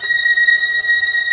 Index of /90_sSampleCDs/Roland LCDP13 String Sections/STR_Combos 2/CMB_StringOrch 1
STR VIOLI0IR.wav